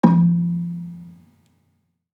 Gambang-F2-f.wav